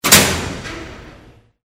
DoorClose2.wav